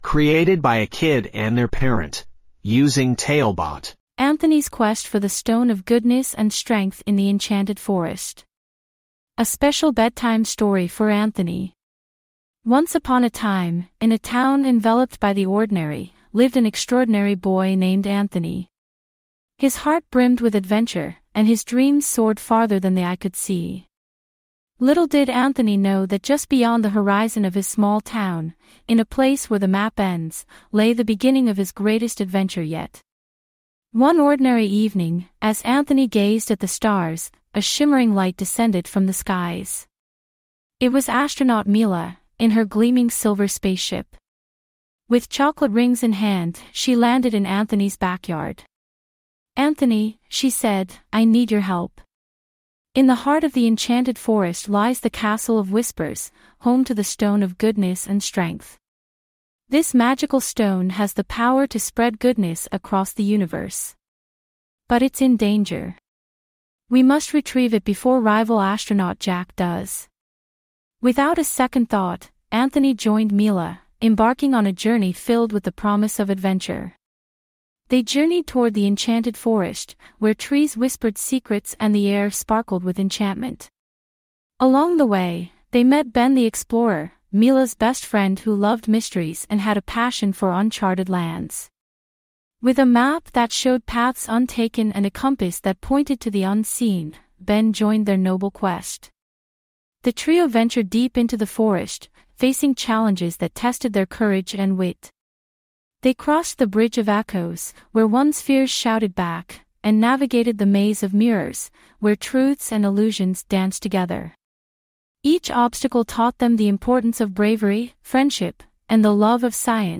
5 Minute Bedtime Stories